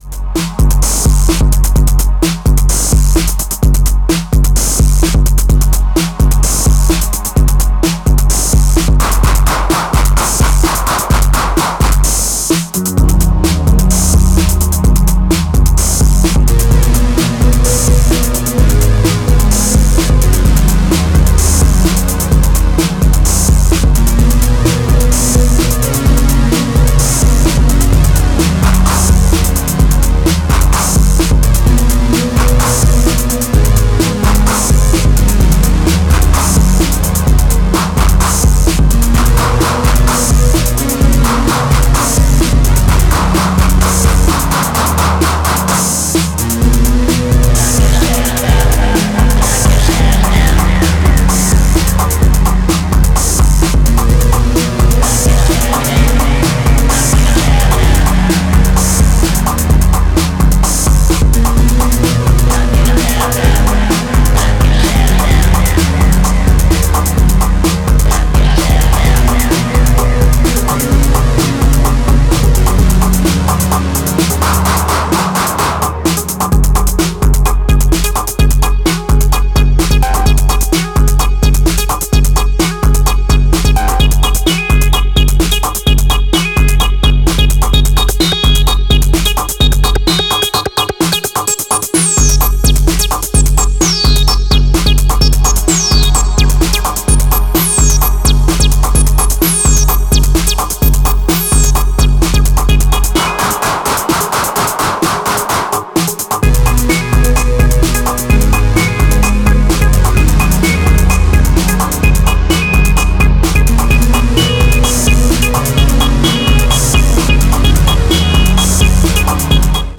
Electro Acid Wave